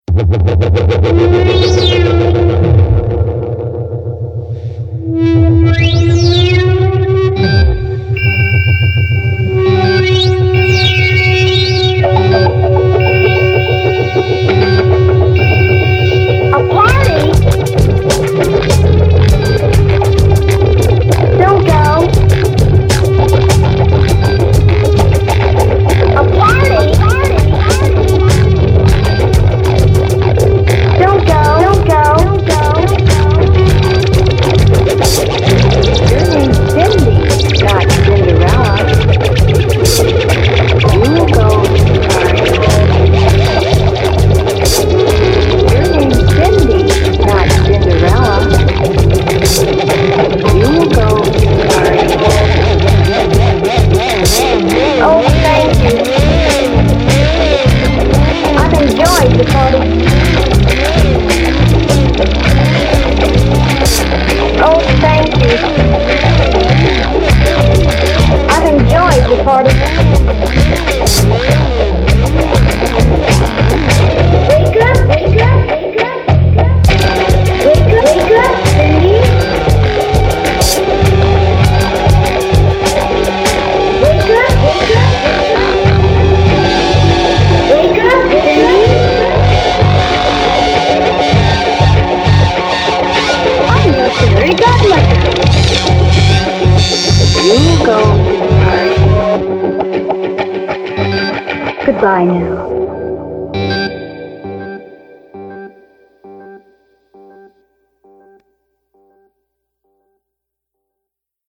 Genre: Acid-Wave.